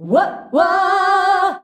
UAH-UAAH G.wav